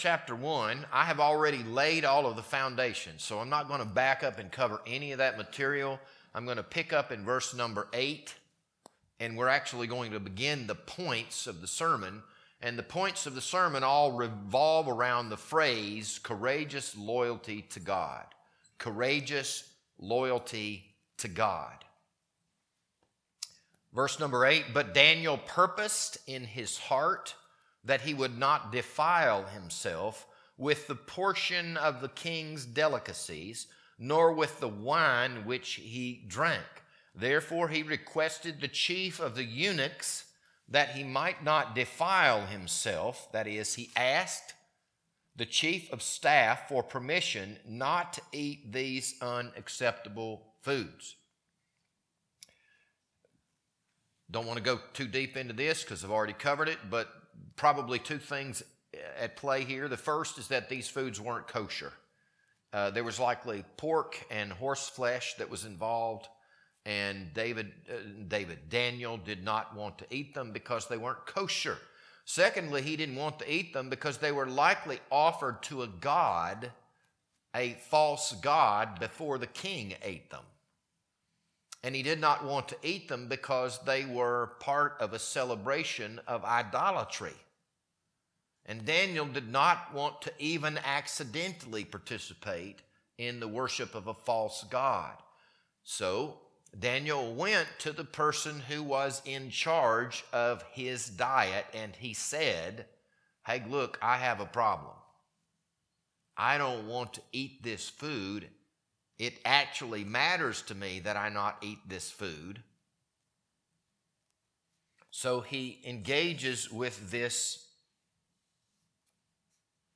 This Sunday evening sermon was recorded on April 19th, 2026.